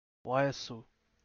ʋ
ವಯಸ್ಸು vayassu 'age' between /v/ and /w/